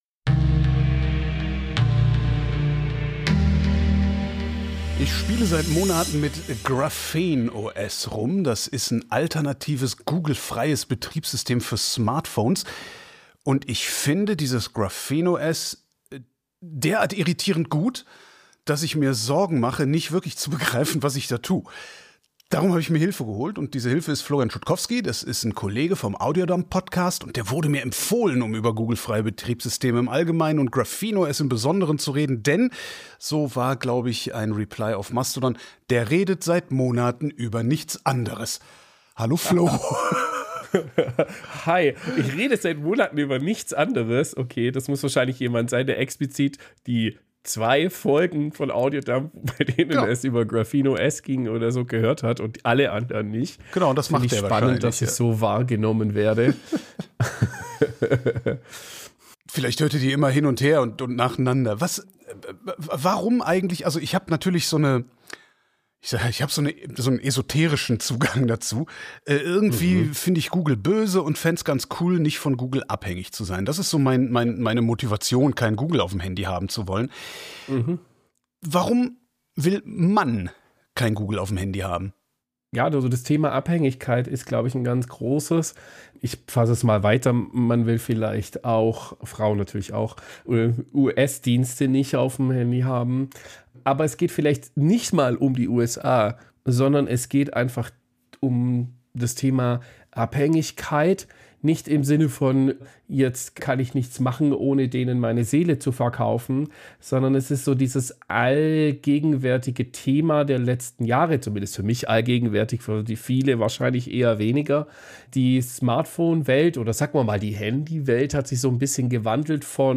InterviewPodcast